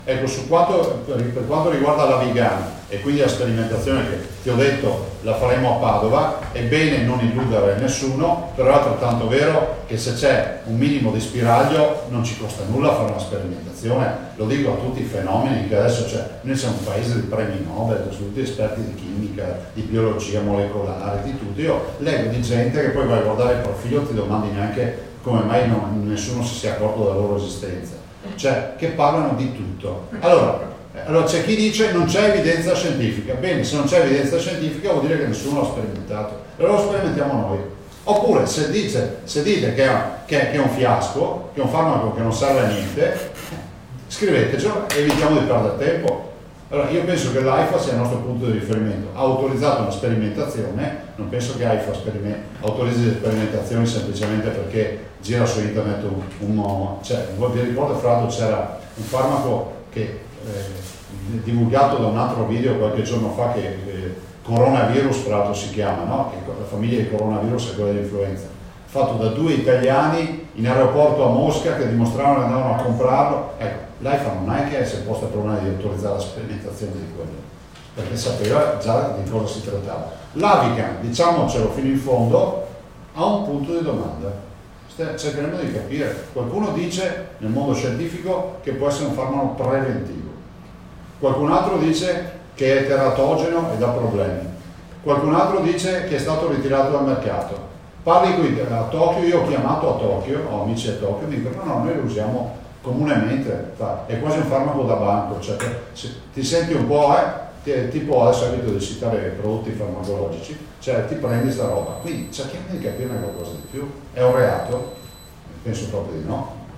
VENEZIA I punti principali della conferenza stampa del Presidente del Veneto Luca Zaia.